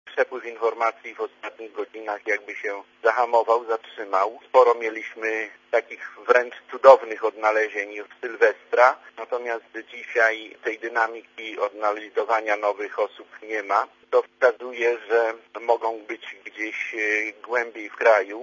Mówi ambasador RP w Tajlandii Bogdan Góralczyk